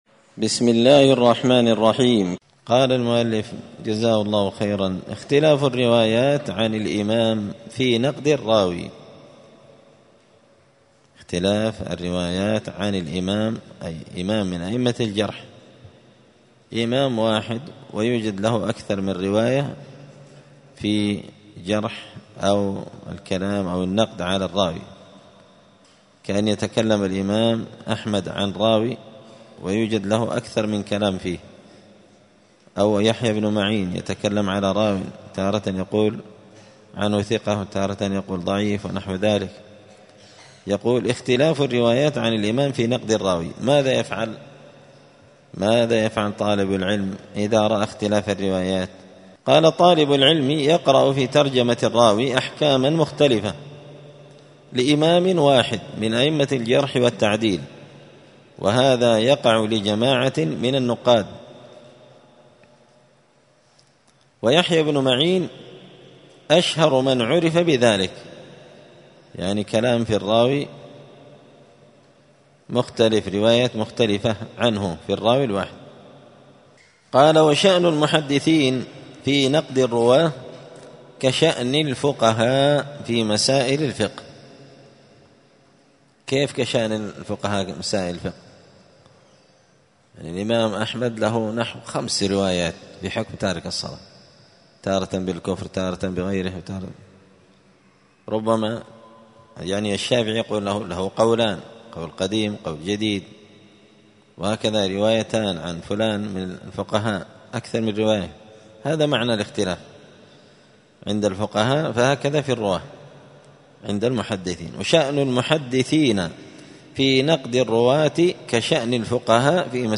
*الدرس الحادي والعشرون (21) اختلاف الروايات عن الإمام في نقد الرواي*